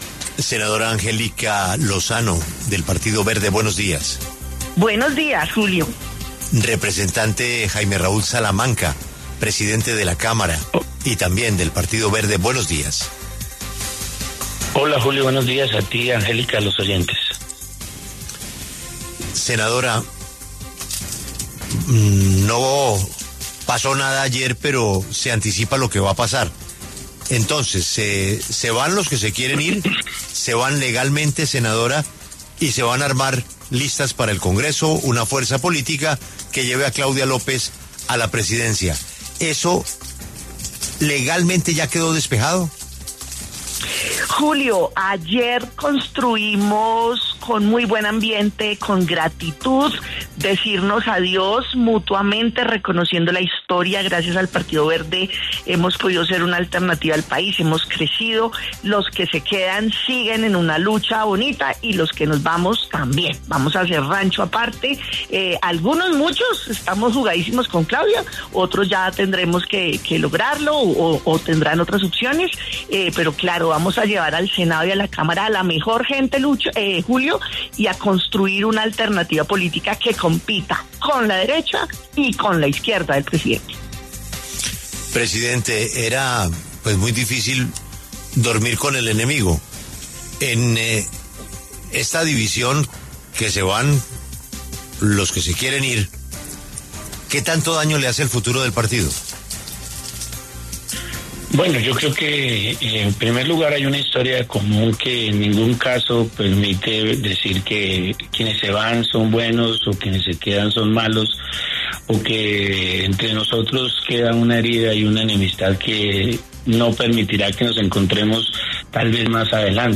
La senadora Angélica Lozano, y el presidente de la Cámara, Jaime Raúl Salamanca, pasaron por los micrófonos de La W.